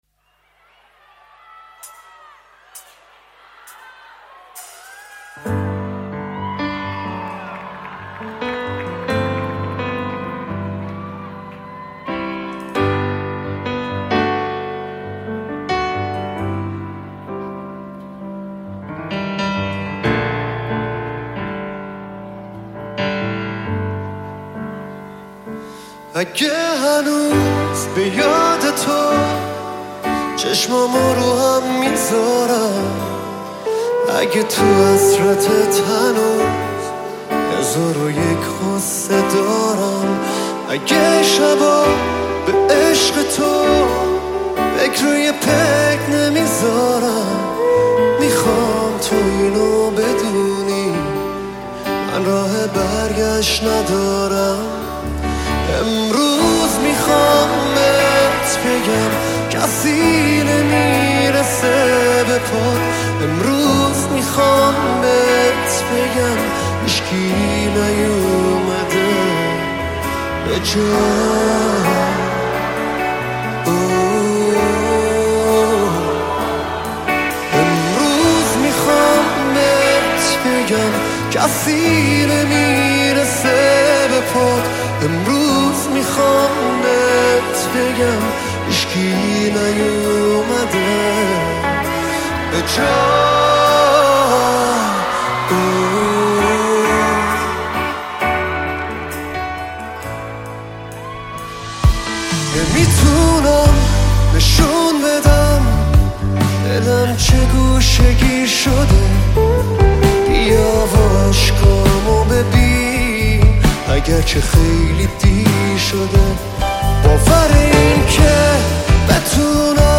Live In Tehran